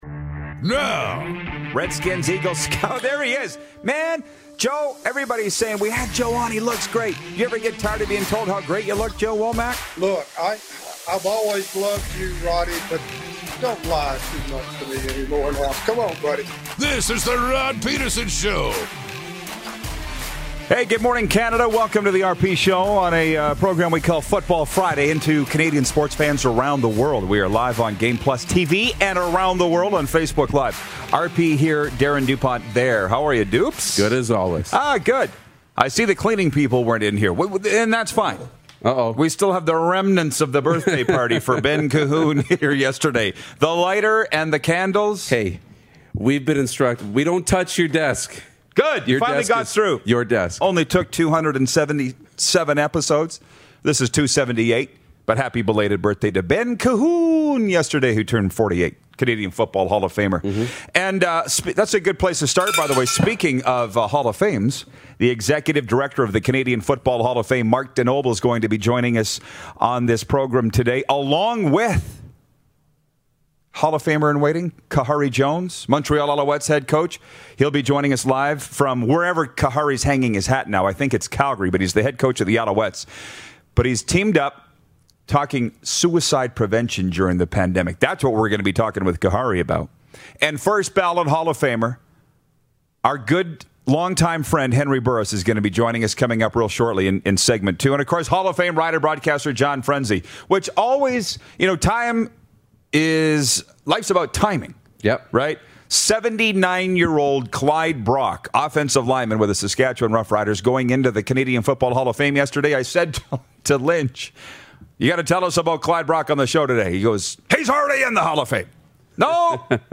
Newly inducted Canadian Football Hall of Famer… Henry Burris joins us on video chat! Head Coach for the Montreal Alouettes and Amabassador for LivingWorks Suicide Prevention… Khari Jones calls in!